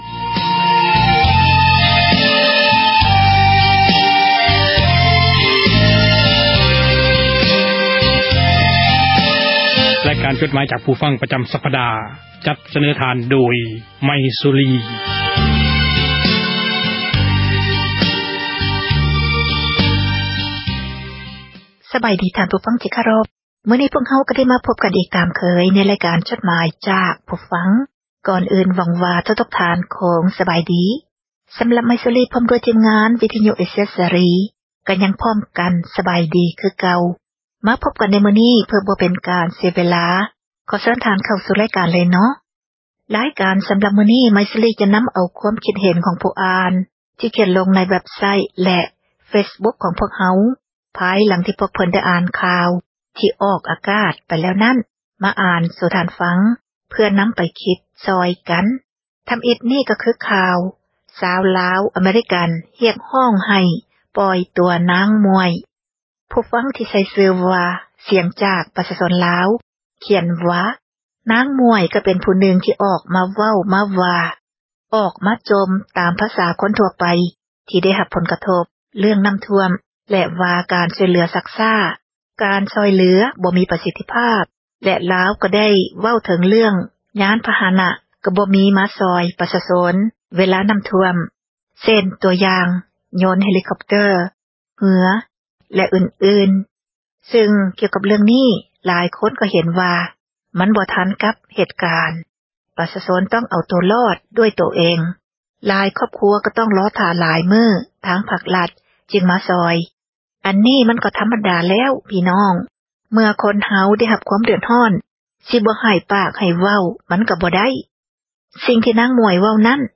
ອ່ານຈົດໝາຍ, ຄວາມຄຶດຄວາມເຫັນ ຂອງທ່ານ ສູ່ກັນຟັງ ເພື່ອເຜີຍແພ່ ທັສນະ, ແນວຄິດ ທີ່ສ້າງສັນ, ແບ່ງປັນ ຄວາມຮູ້ ສູ່ກັນຟັງ.